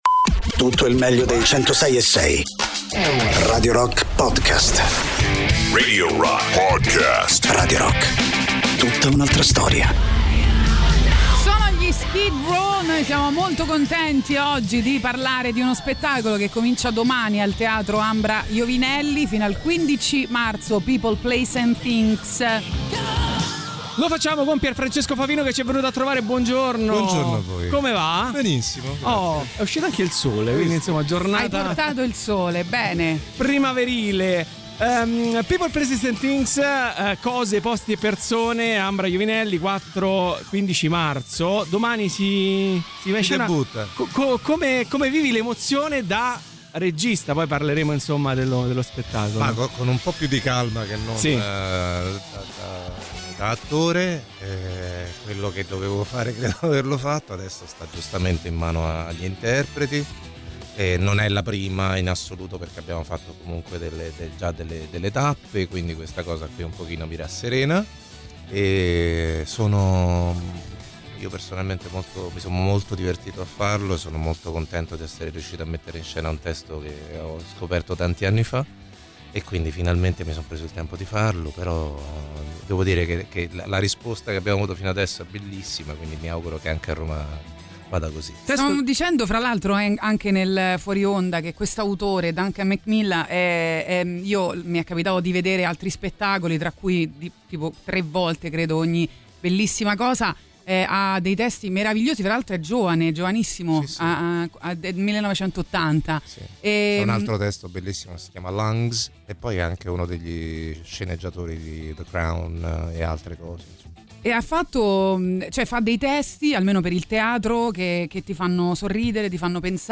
Intervista: Pierfrancesco Favino (03-03-26)